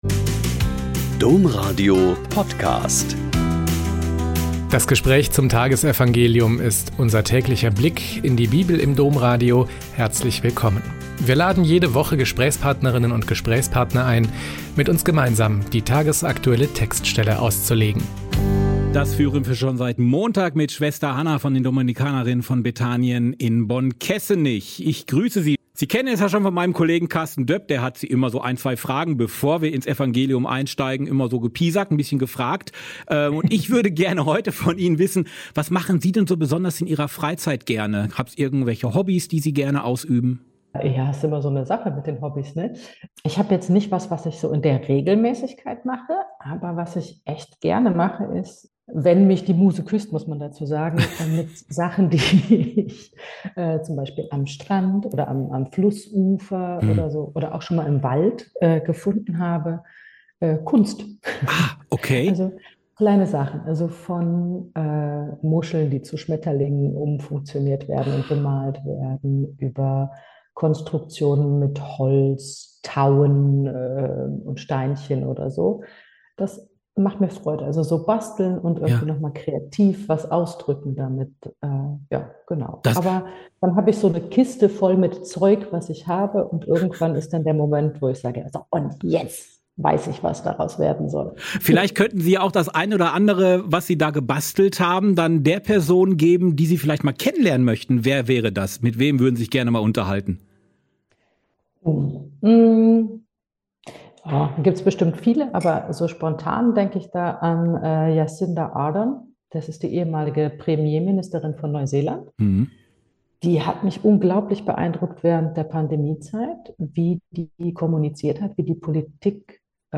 Joh 4, 19–24 - Gespräch